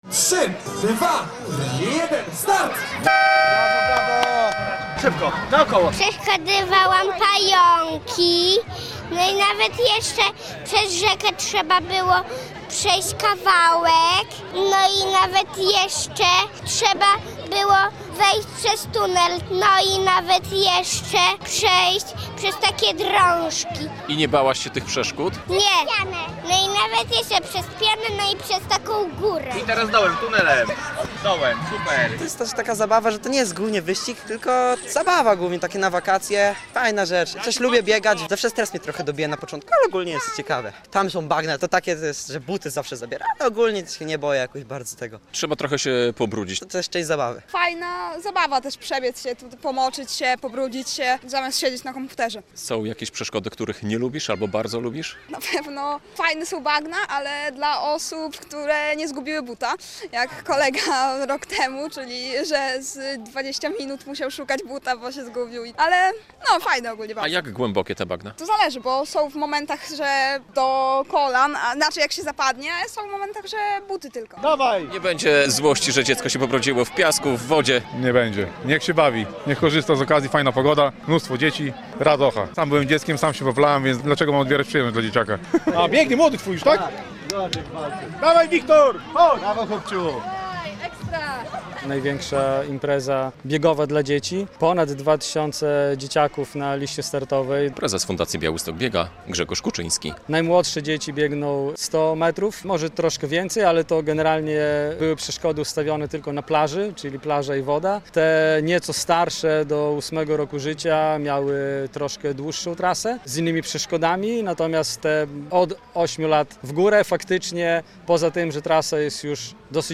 Hero Run Kids na białostockiej plaży Dojlidy - relacja